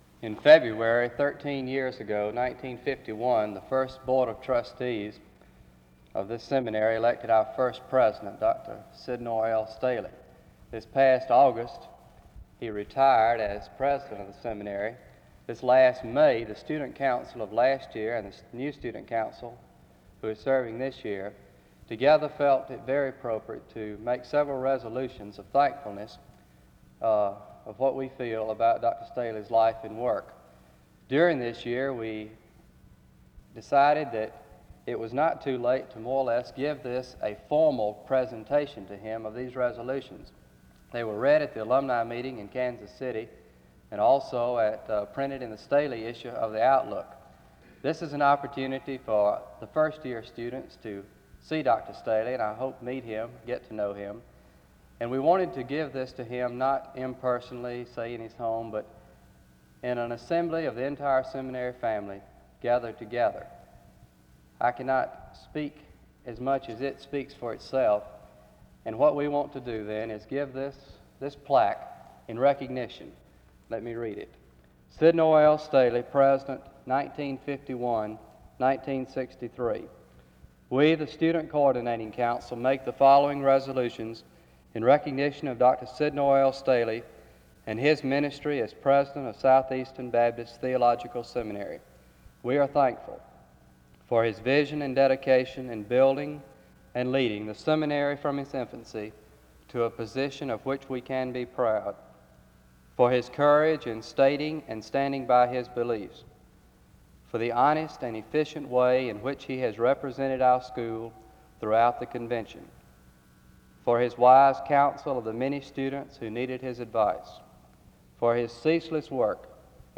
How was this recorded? There are announcements and prayer from 5:00-6:34. He notes that Christians are to walk in humility and worthy of their calling. This service was organized by the Student Coordinating Council.